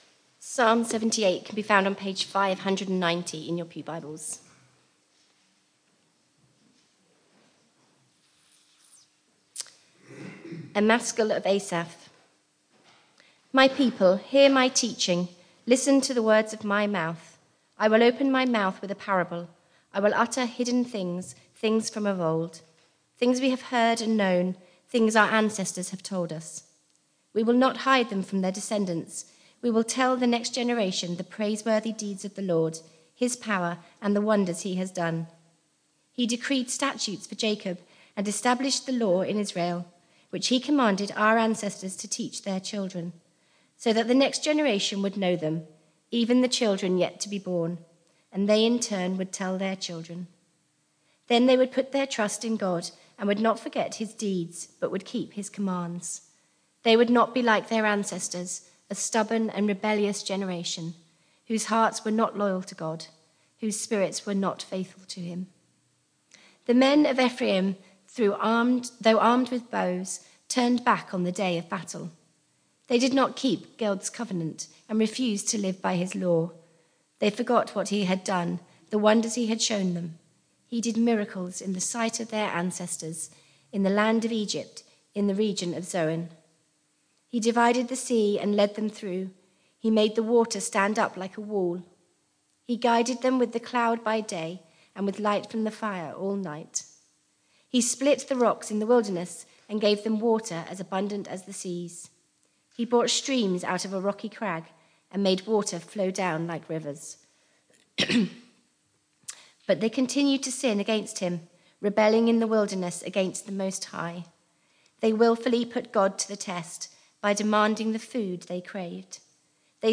Media for Barkham Morning Service on Sun 10th Aug 2025 10:00
Sermon